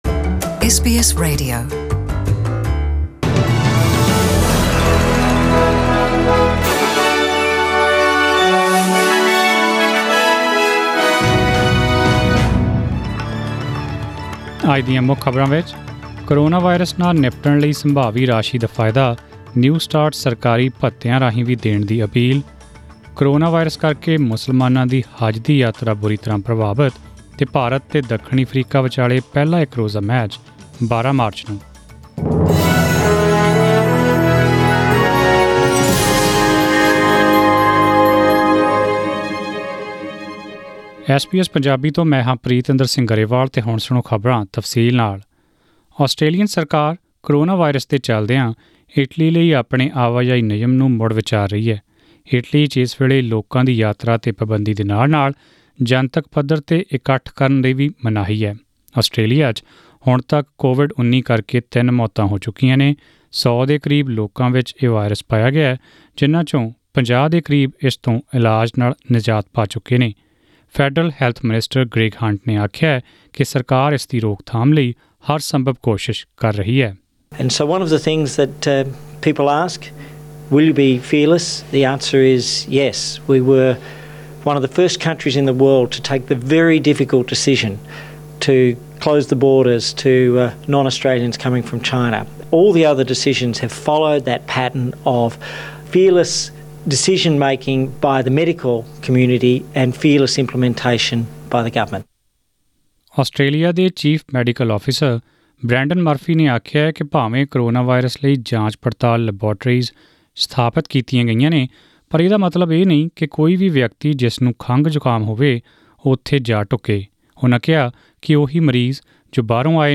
In this bulletin,